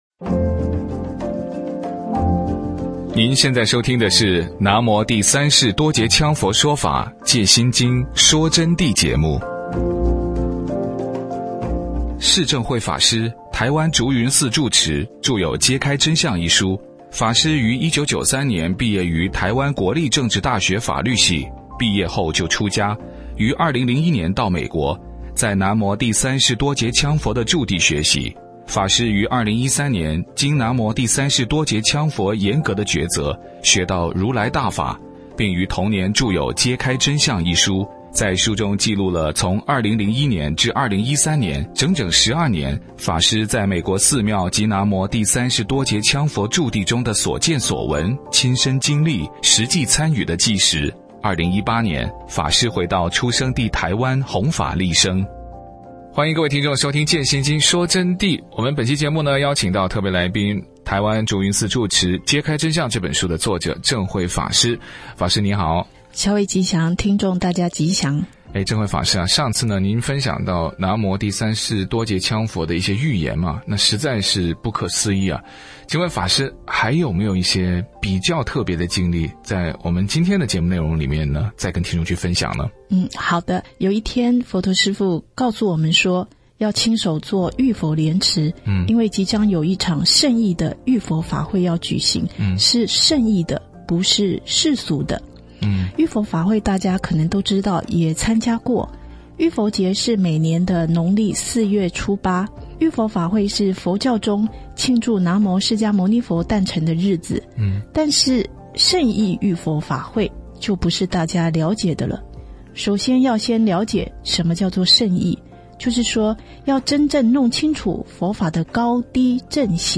佛弟子访谈（四十七）胜义佛法与世俗佛法的区别；南无羌佛预言，法会强风雷鸣圣境显